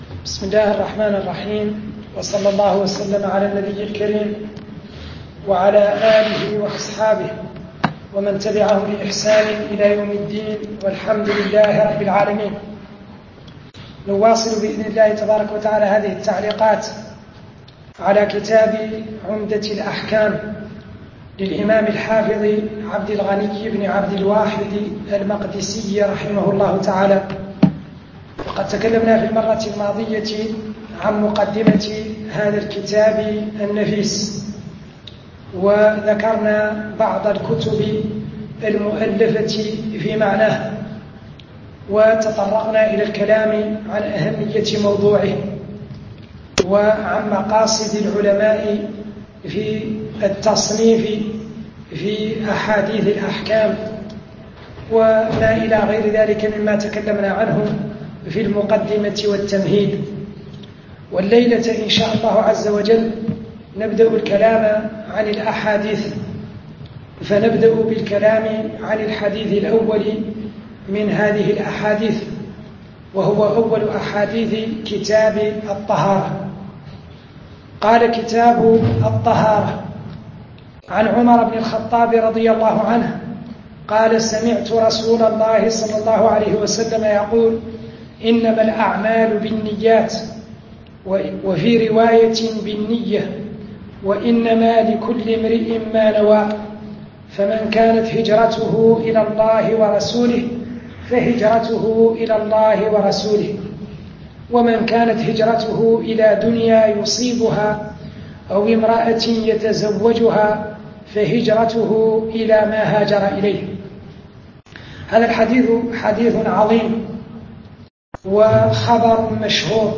شرح عمدة الأحكام من كلام خير الأنام الدرس الثاني